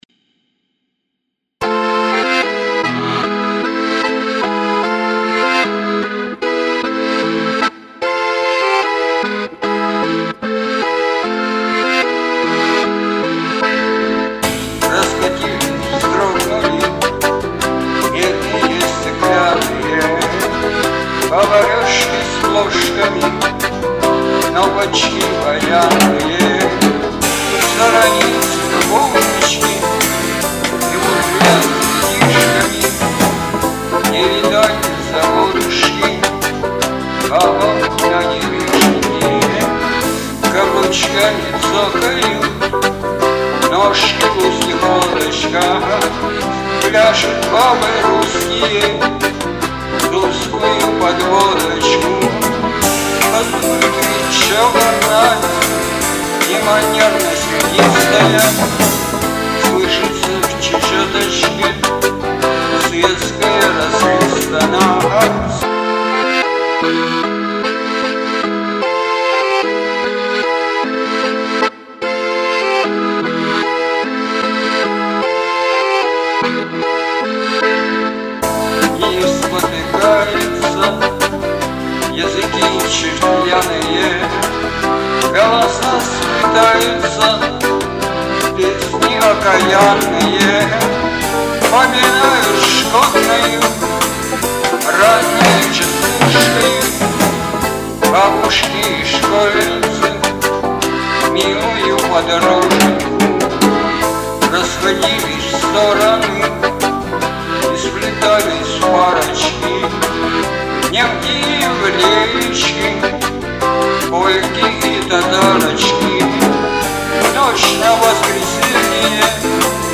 • Жанр: Латинская